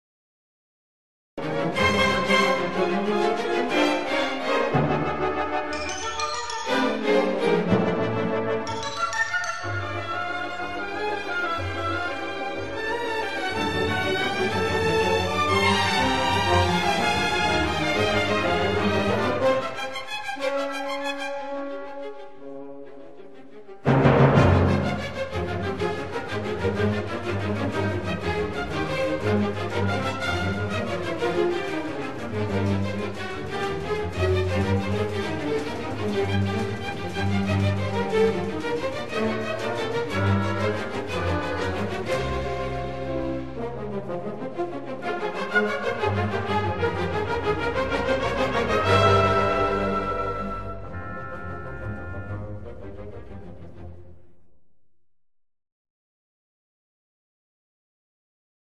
Konzertstück